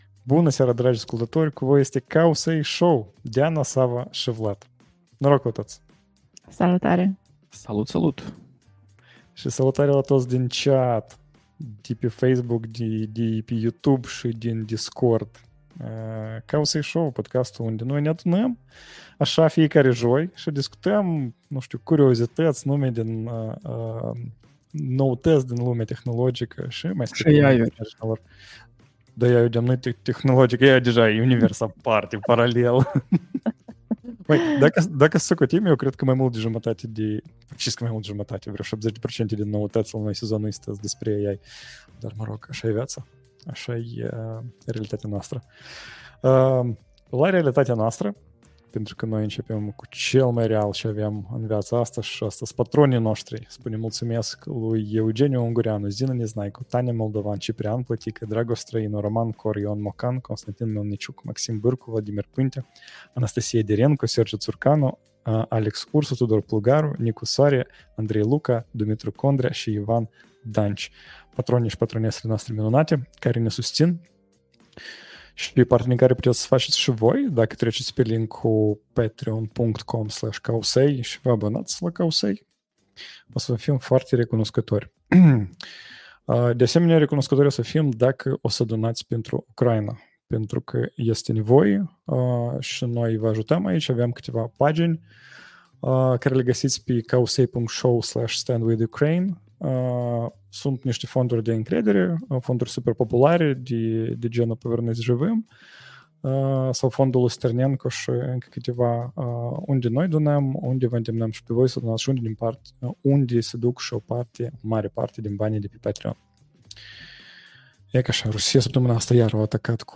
#122: Record de fundraising de la OpenAI, 600m parole in plain text, Haltere acum și cu AI October 3rd, 2024 Live-ul săptămânal Cowsay Show.